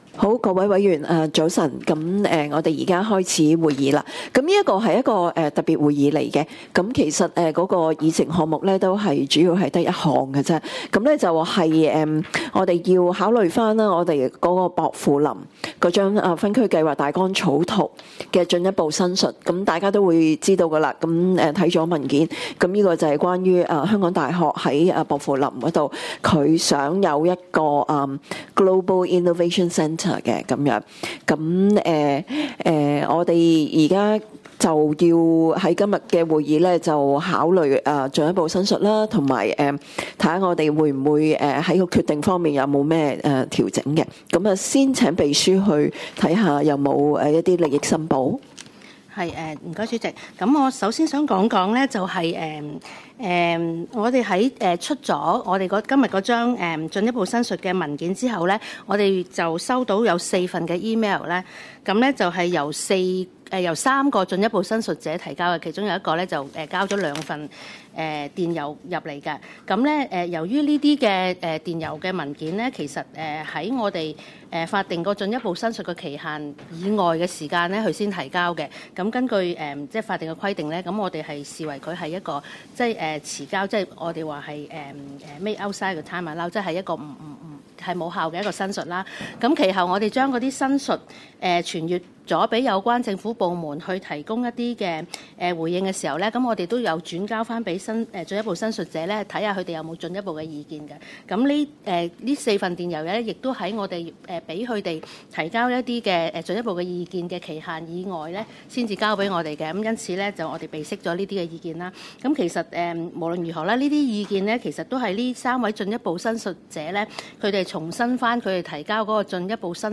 Consideration of Further Representations on Proposed Amendment to the Draft Pok Fu Lam Outline Zoning Plan No. S/H10/22 Arising from Consideration of Representations on the Draft Outline Zoning Plan (Open Meeting) Listen/Download